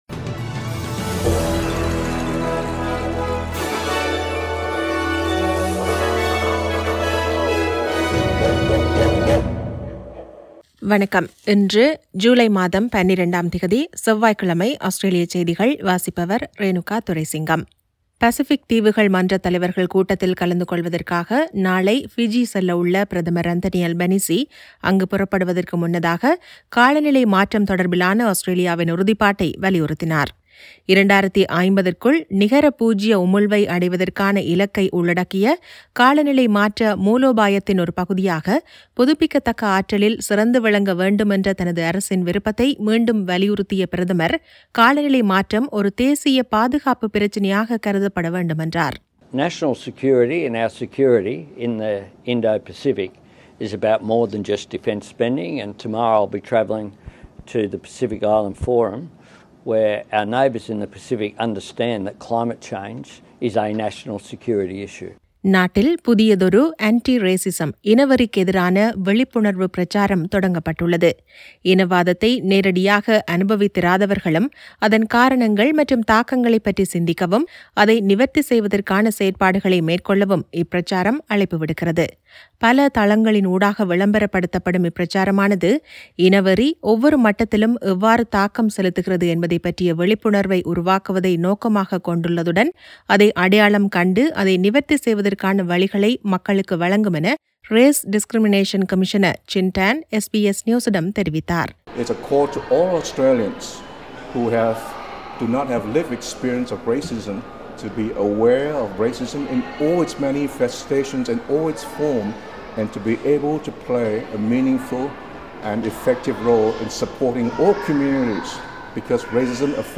Australian news bulletin for Tuesday 12 July 2022.